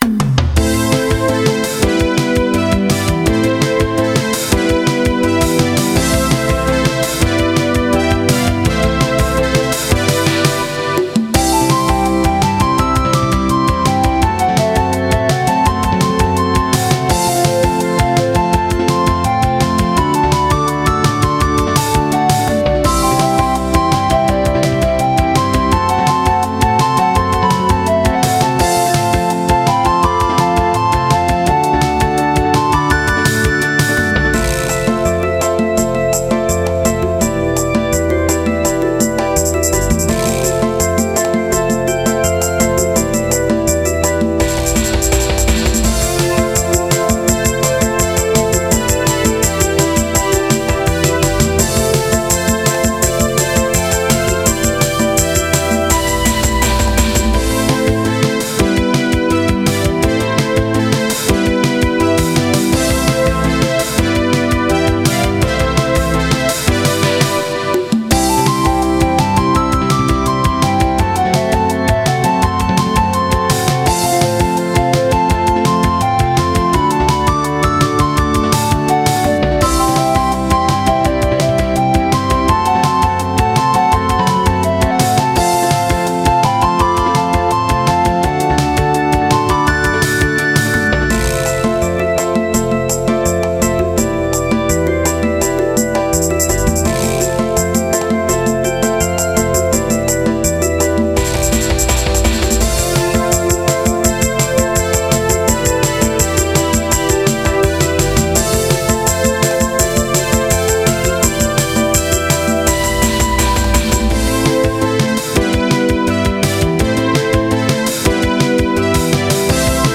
気にも留めない時の流れを感じる曲です。